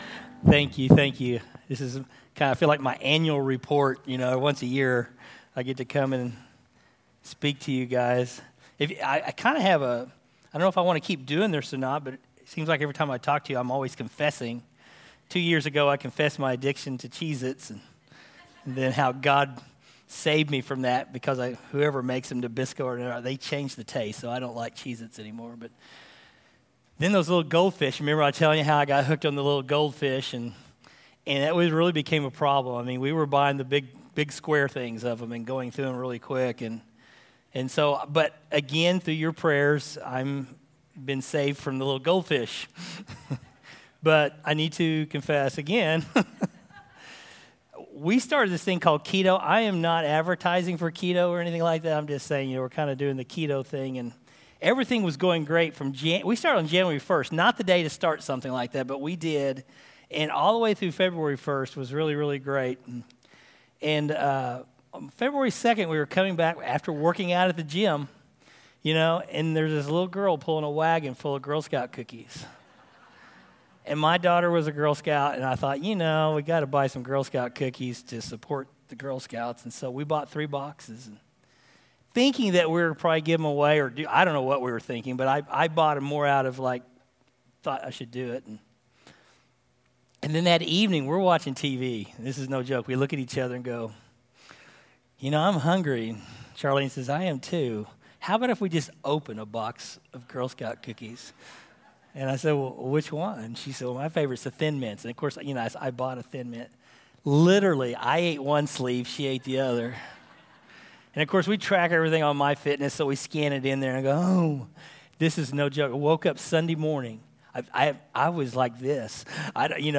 From Series: "2019 Sermons"
Our 2019 sermons that aren't part of a "series".